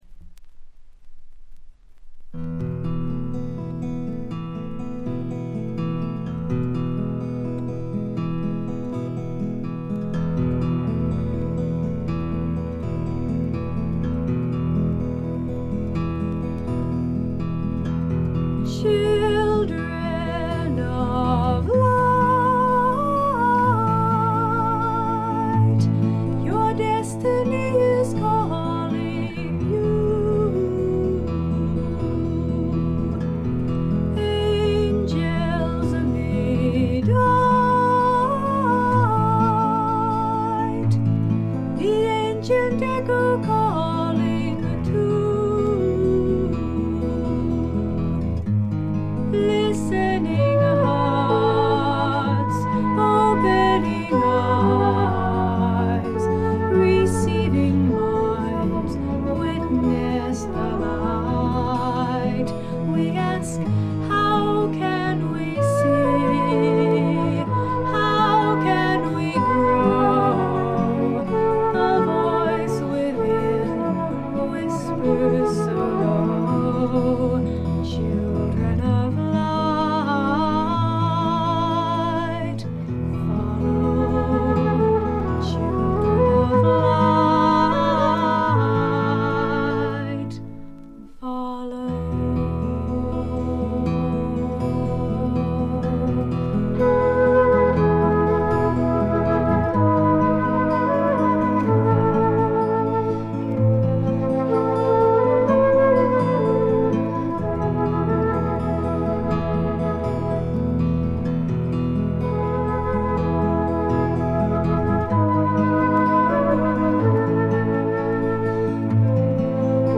ほとんどノイズ感無し。
カリフォルニア産クリスチャン系フィメールフォークの名作です。
裏ジャケの写真そのままに、まだ少女らしさを残した清楚な歌声はおぢさんキラーぶりもいかんなく発揮しておりますね。
フルートがたなびくちょっとアシッドな香り、メローな感覚、ダークで静謐な曲が特に良いですね。
試聴曲は現品からの取り込み音源です。